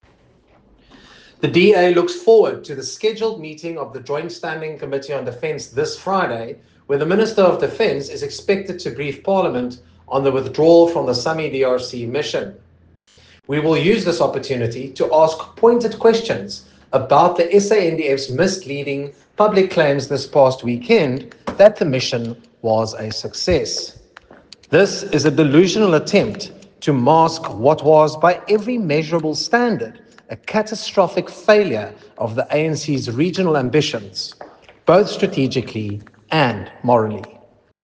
soundbite by Nicholas Gotsell MP